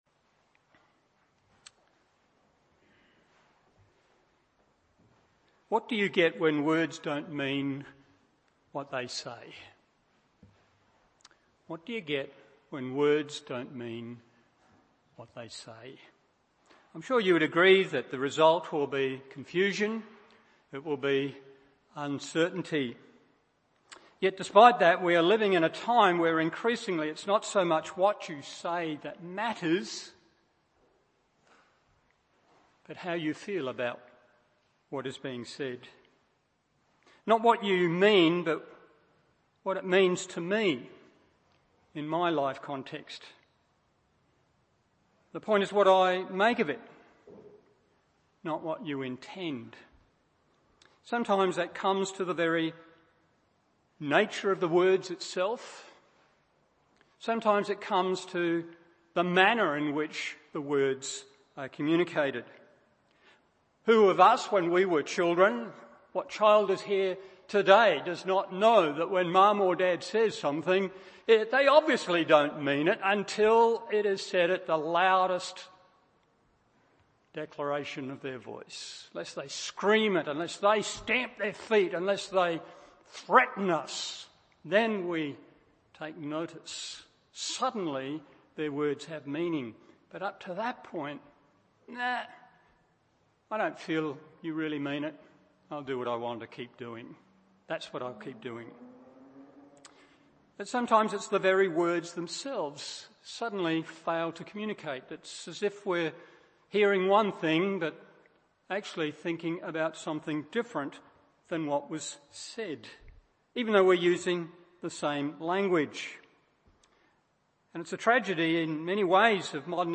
Morning Service Matt 5:33-37 1. The Practice Displayed 2. The Problem Diagnosed 3.